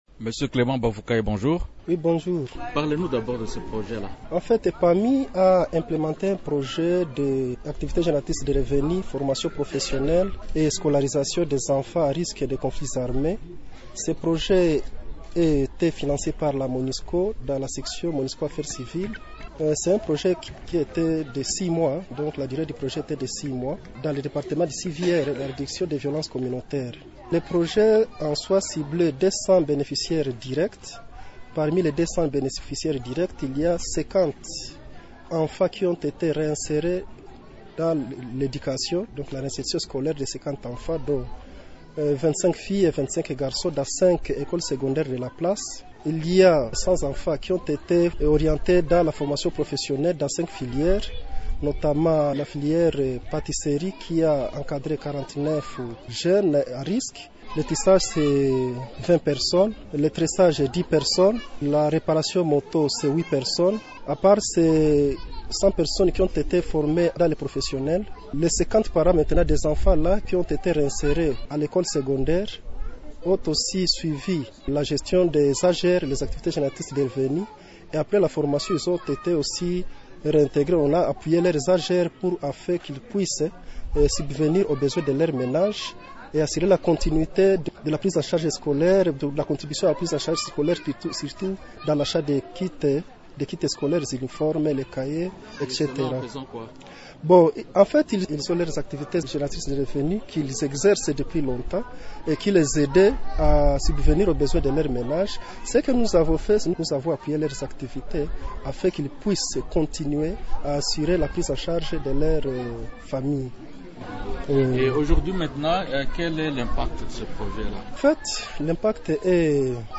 L'invité du jour, Émissions / milice, Appel de la paix, Ituri, Djugu, le général Antoine David Mushimba